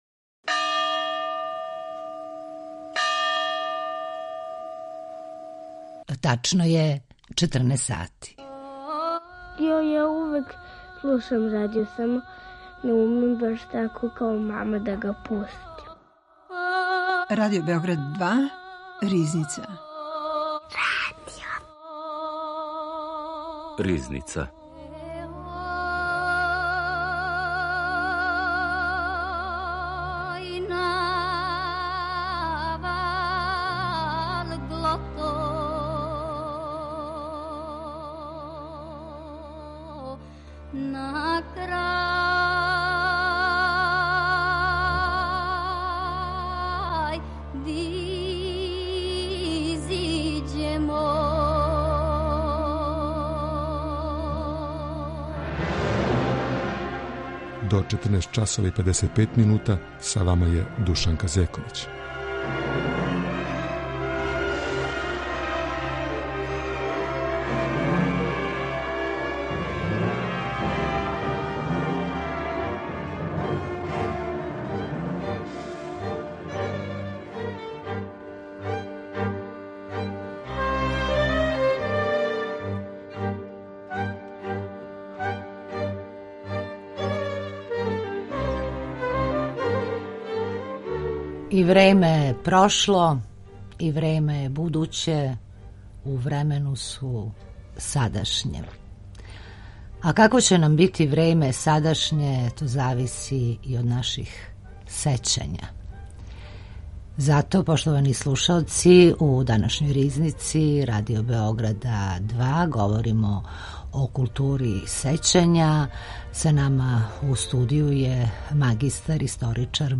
С њим ћемо разговарати о тој изложби, важности таквих пројеката, култури сећања и српским стратиштима током Другог светског рата. Аутор и водитељ